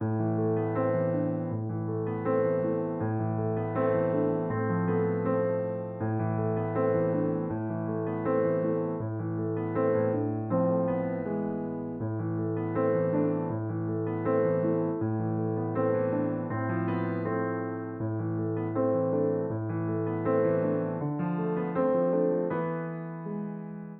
Dark Keys 3 BPM 80.wav